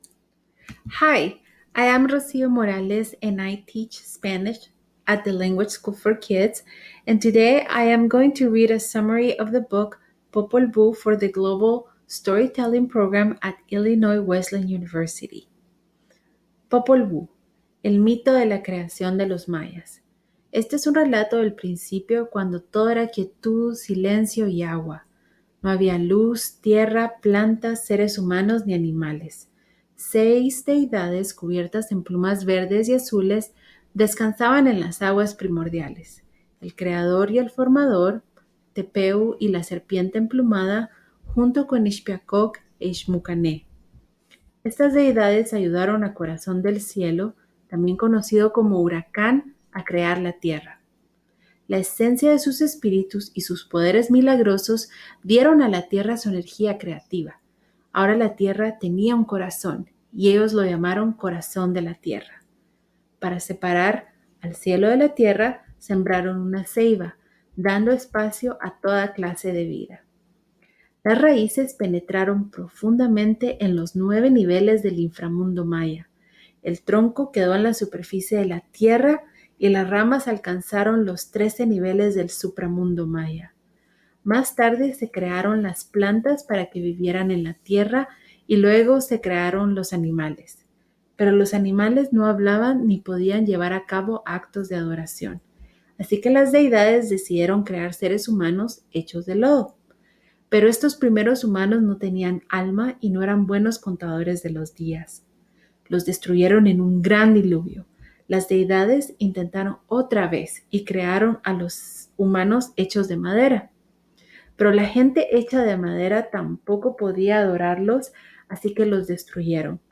Oral History Item Type Metadata